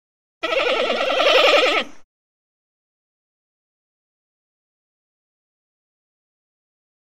Звуки панды
Панда тихо блеет